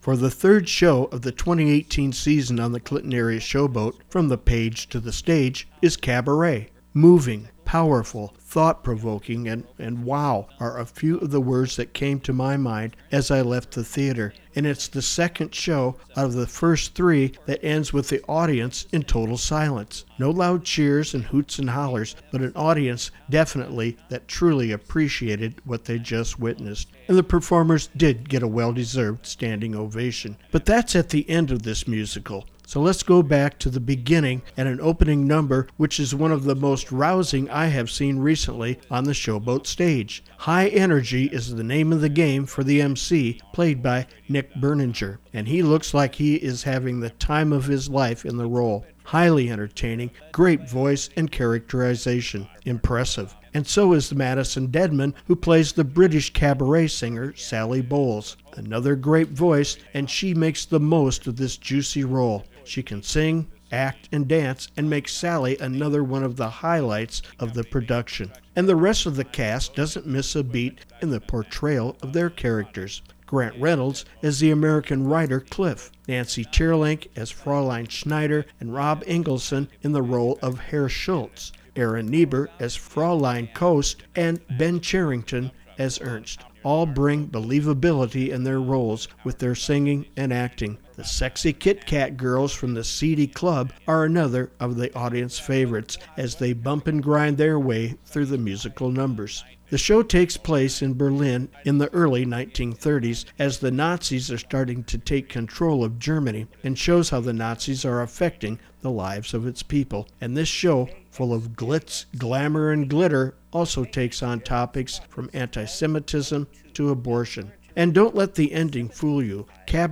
Cabaret-Review-7-12-18.wav